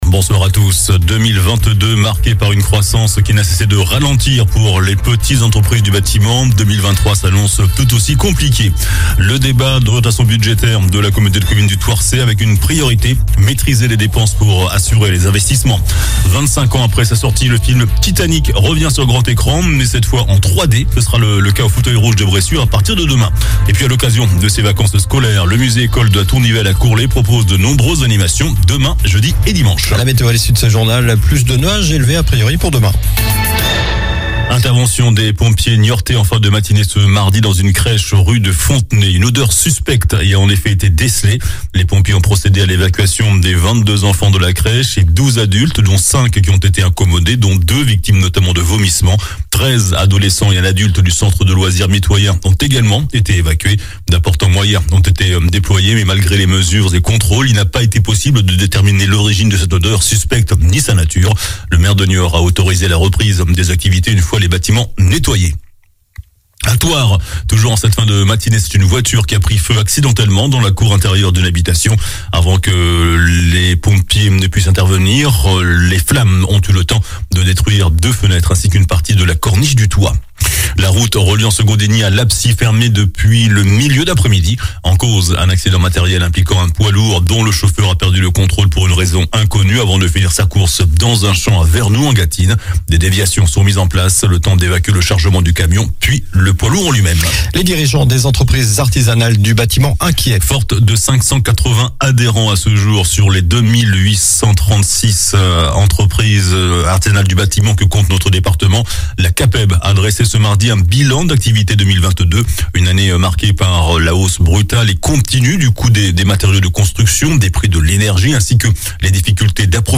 JOURNAL DU MARDI 14 FEVRIER ( SOIR )